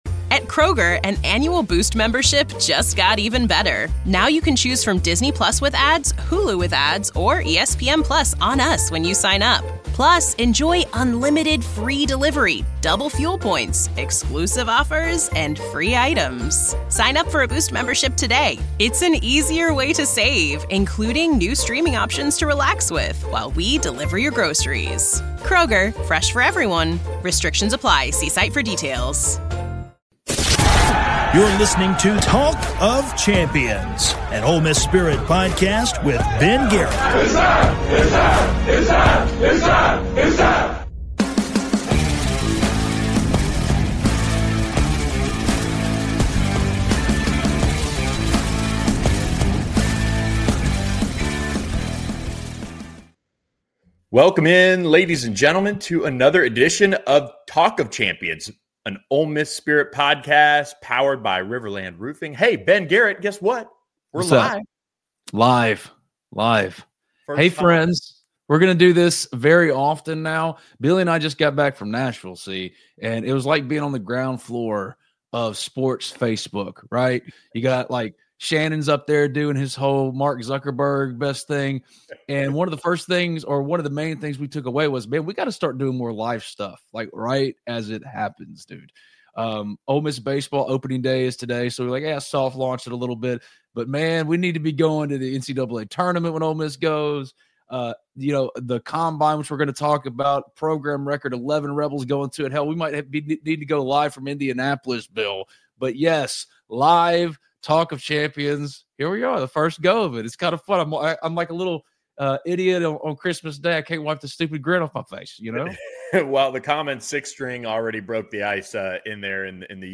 Talk of Champions goes LIVE